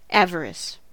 avarice: Wikimedia Commons US English Pronunciations
En-us-avarice.WAV